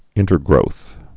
(ĭntər-grōth)